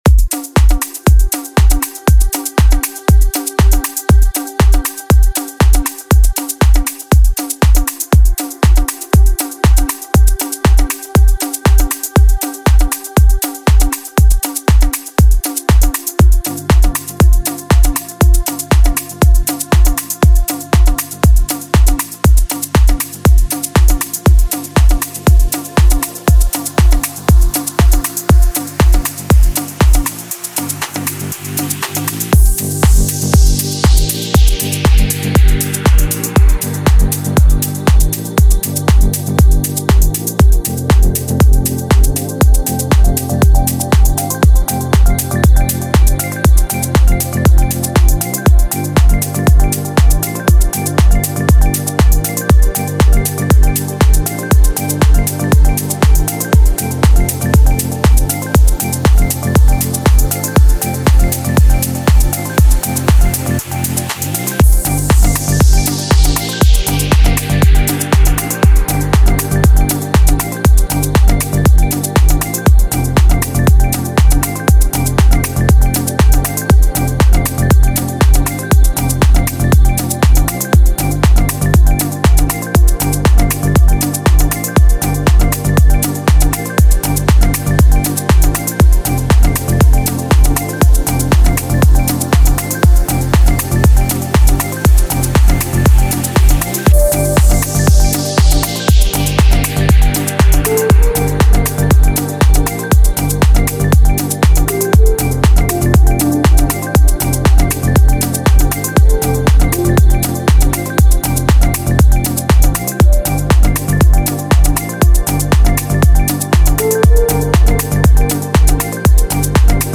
Deep House House